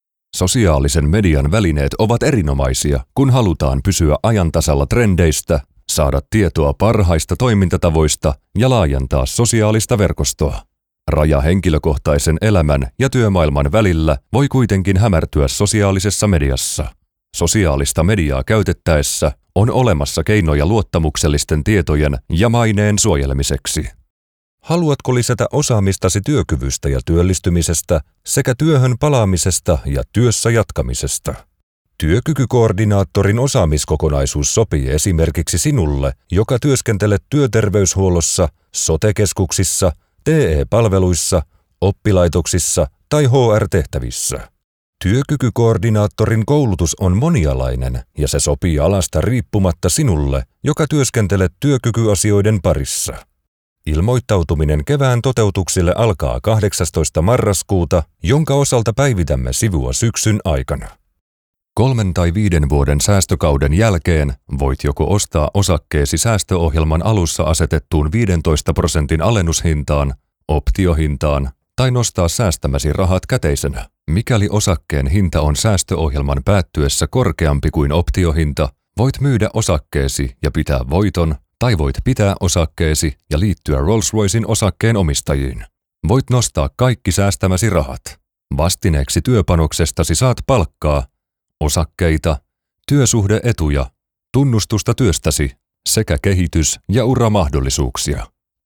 Deep, Reliable, Commercial, Corporate, Warm
Corporate